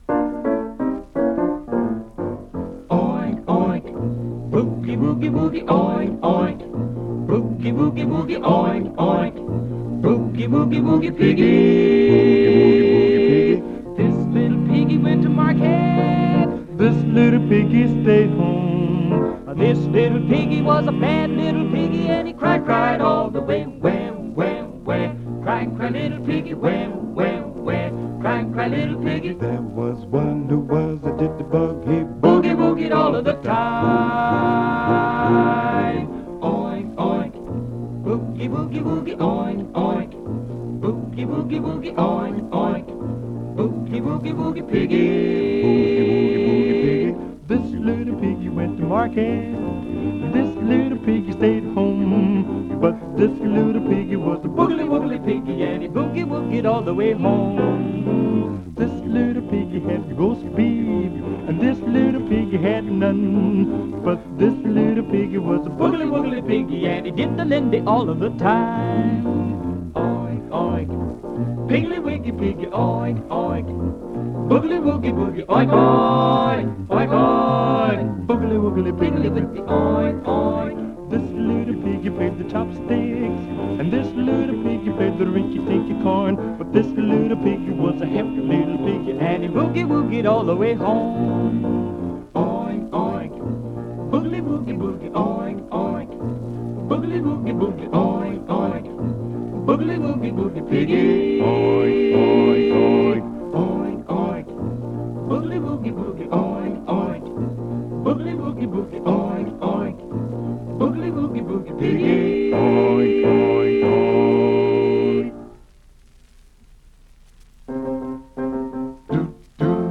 a series of radio recordings